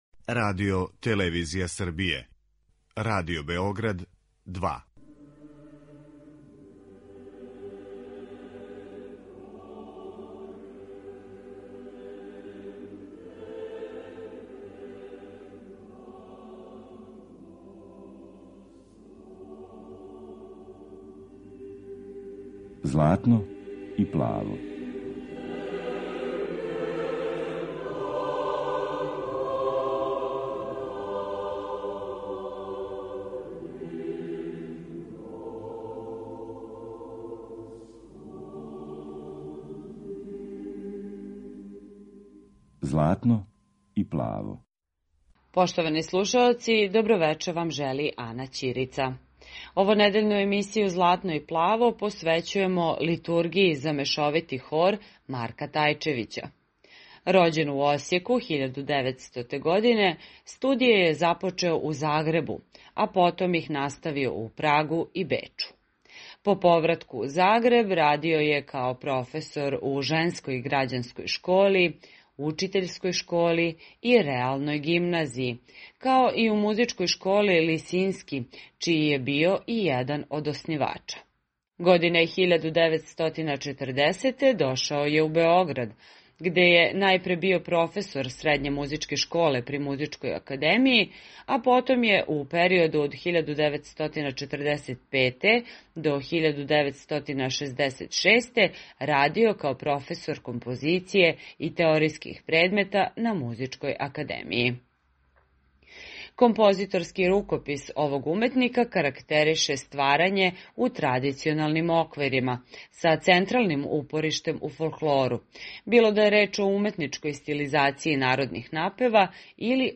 мешовити хор
Емисија посвећена православној духовној музици.